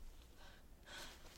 沉重的呼吸 " 沉重的呼吸3
描述：用SM58和MOTU 828 MK2录制独立恐怖游戏
Tag: 重呼吸 呼吸 吓得